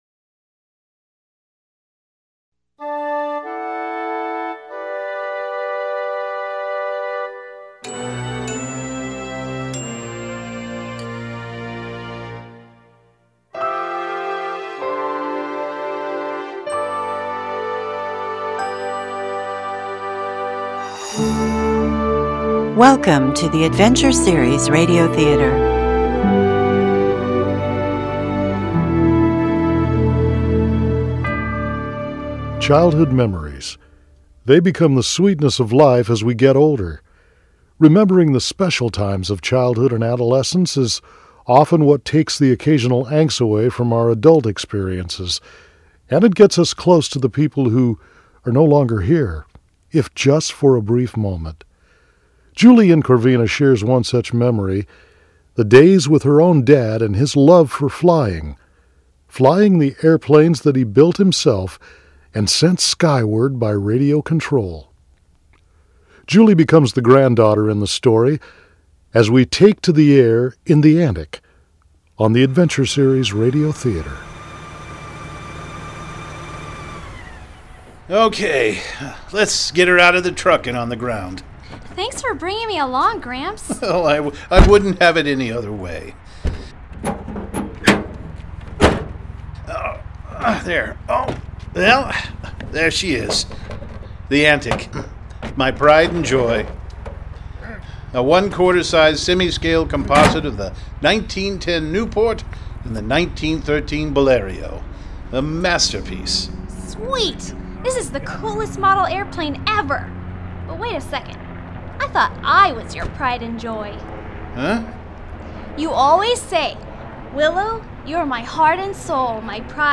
These recording made in my home studio…